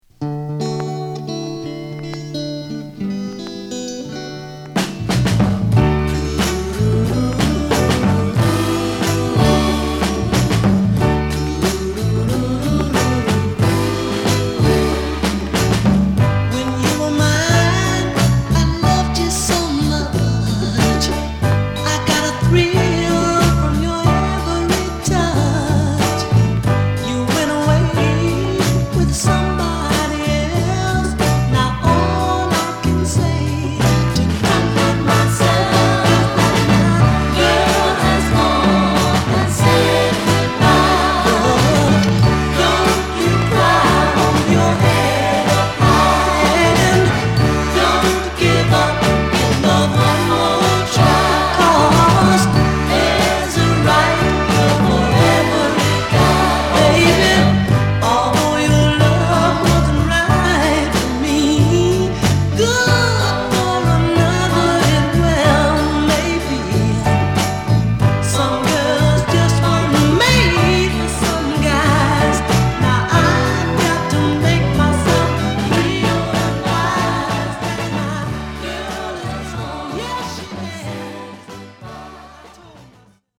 骨太のリズム隊と郷愁のメロディが絡む期待を裏切らない60s沁みソウルを披露！